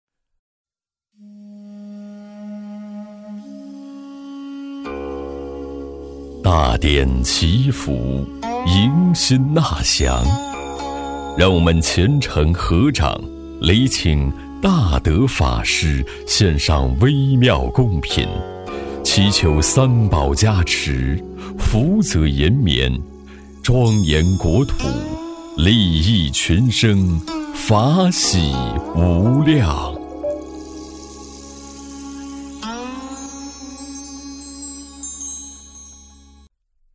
男国167厚重配音-新声库配音网
5 男国167_专题_宗教_祈福大典_沉稳 男国167
男国167_专题_宗教_祈福大典_沉稳.mp3